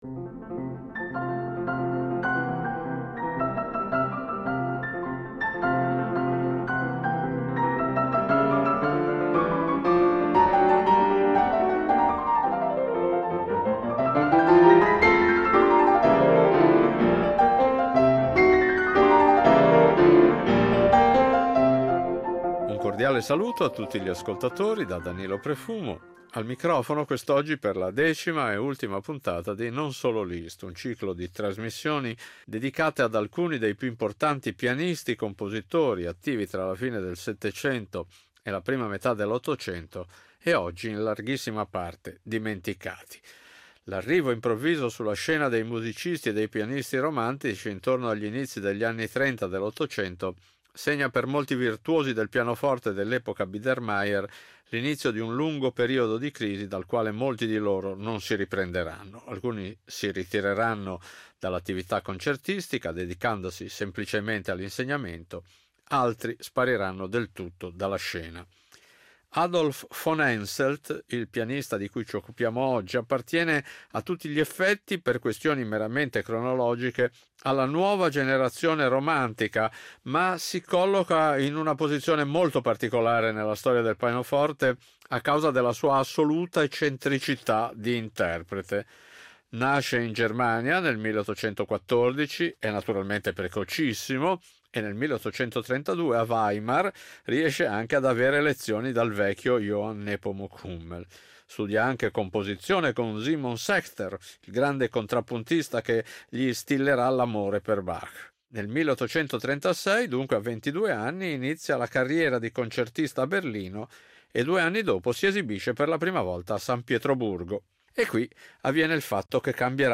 Vengono presentati il Poème d’Amour Opera 3 e la Valse Mélancolique Opera 36 , e il movimento lento del suo Concerto in Fa minore per pianoforte e orchestra Opera 16 , eseguito da Marc-André Hamelin.